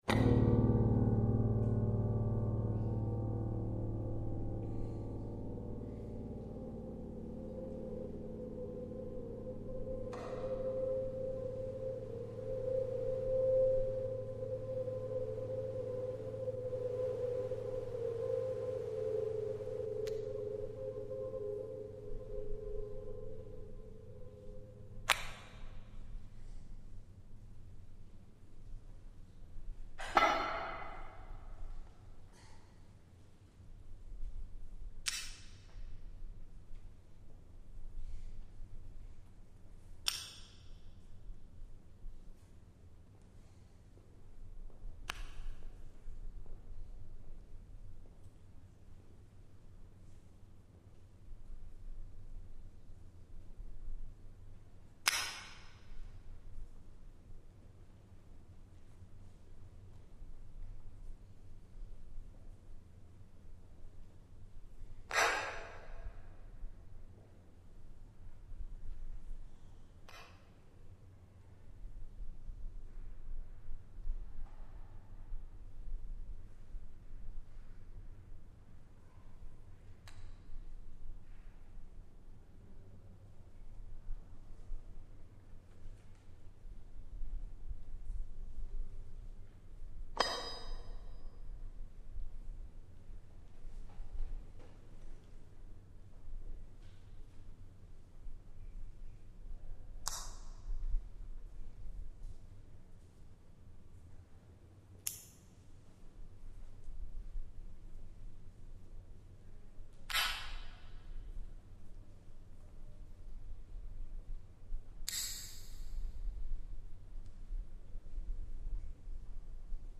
Conceptual Space premiere mixed from a live recording in the Conceptual Gallery at the Nelson-Atkins museum
Quad sound